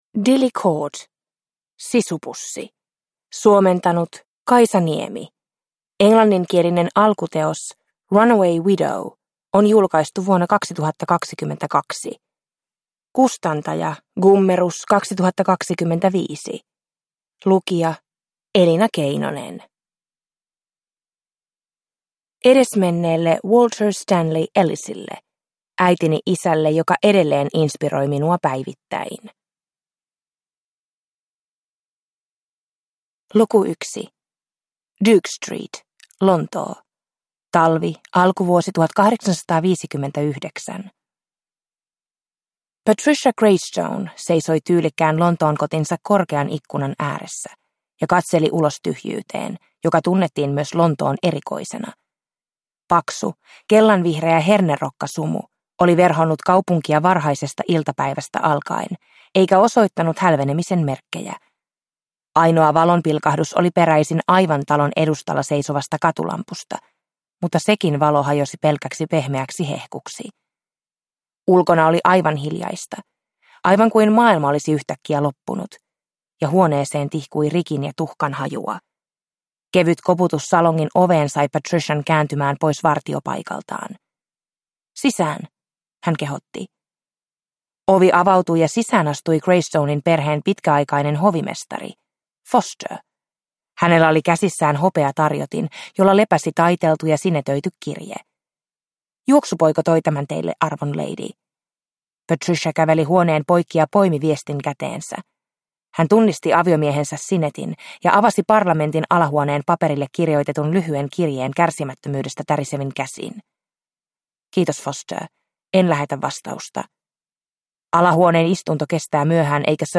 Sisupussi (ljudbok) av Dilly Court